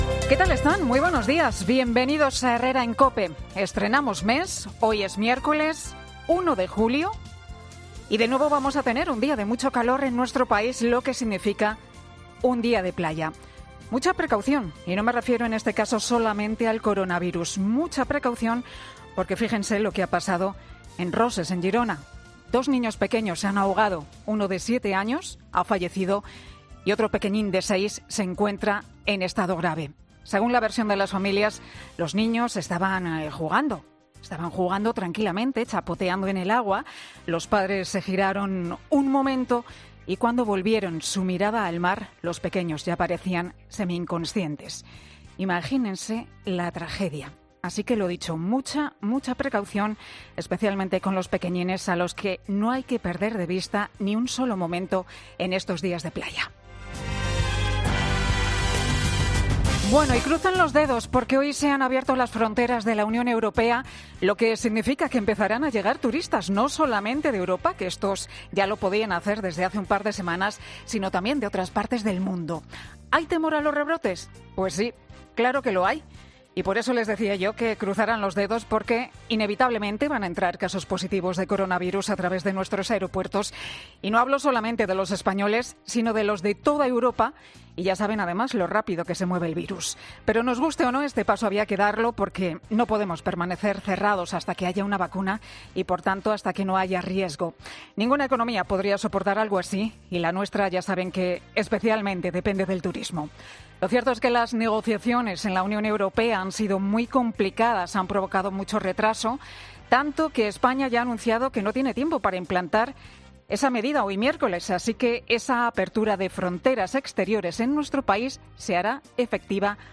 Tocará su gaita en memoria de todos los fallecidos por esta pandemia.